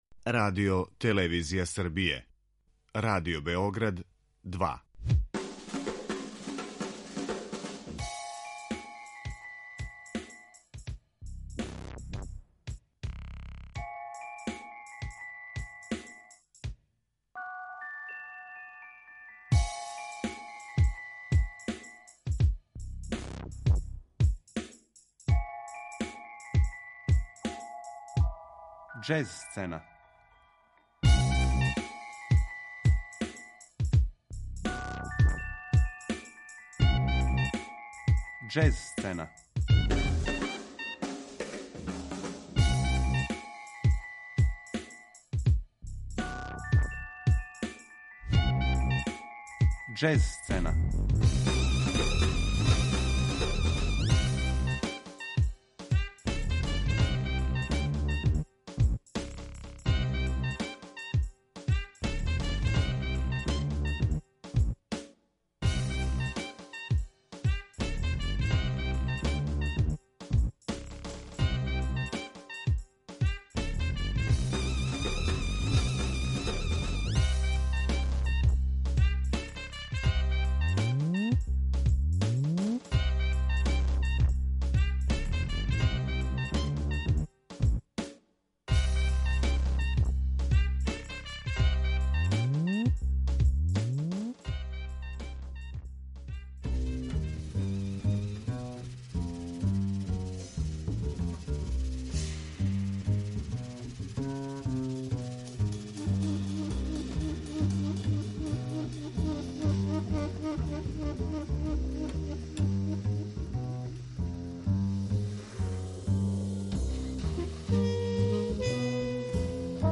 Белгијска џез сцена